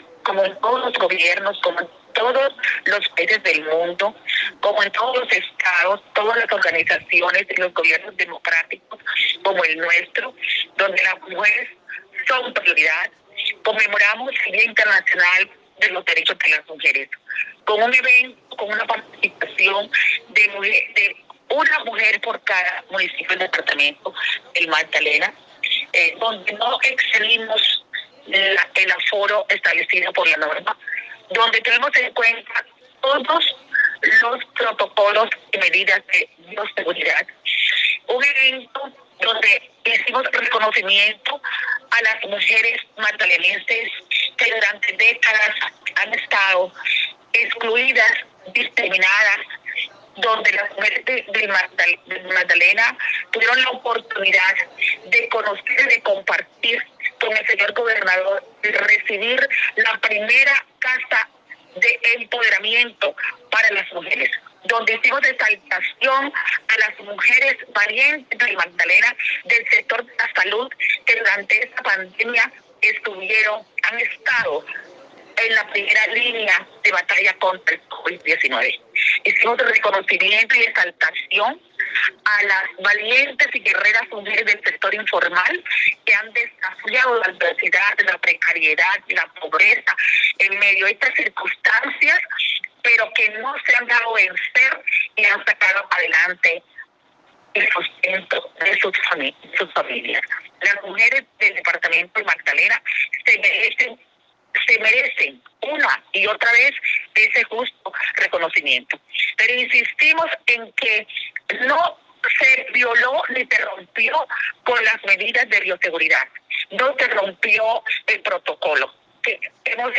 DECLARACIONES-SECRETARIA-DE-LA-MUJER-KARLINA-SANCHEZ.mp3